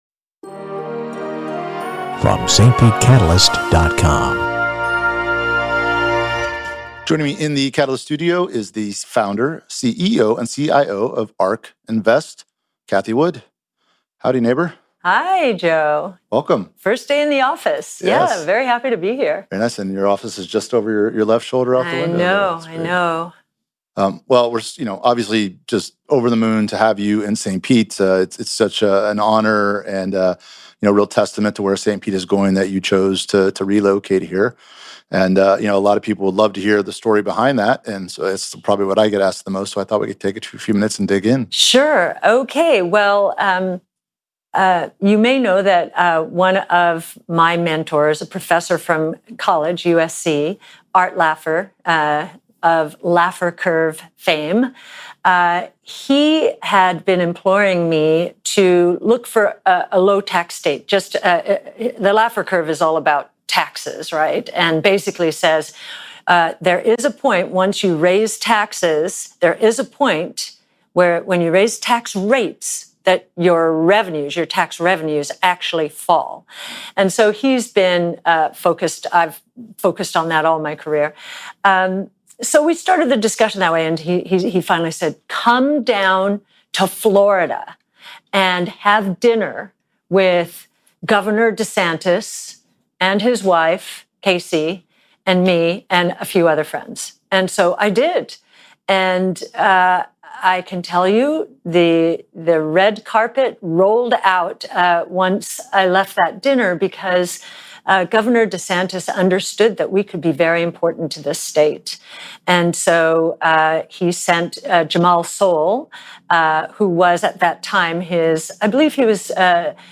Ark Invest's Cathie Wood in Catalyst Studio 11-5-21